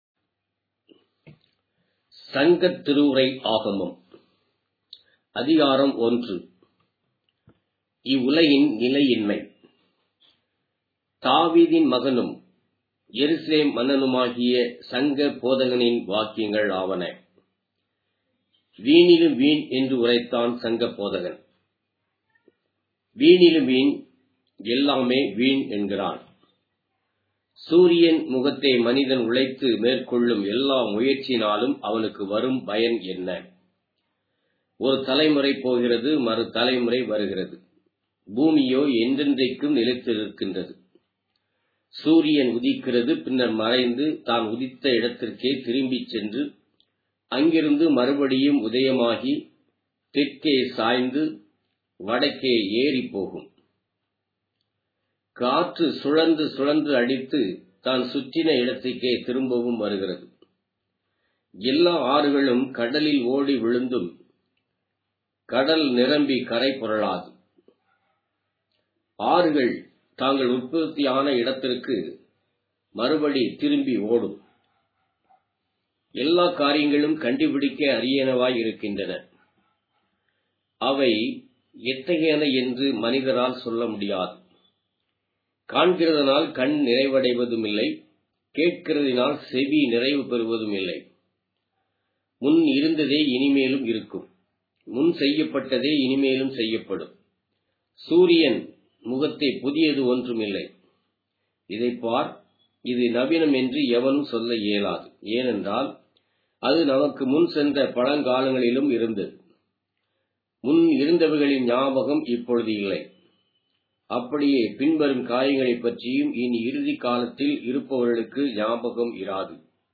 Tamil Audio Bible - Ecclesiastes 12 in Rcta bible version